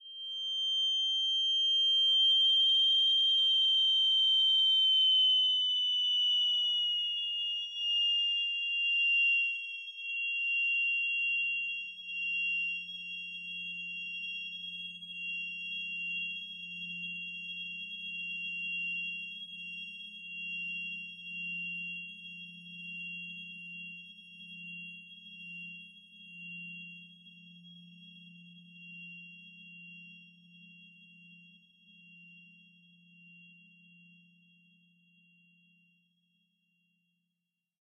spearYellRumbleTinnitusProlongVoice.ogg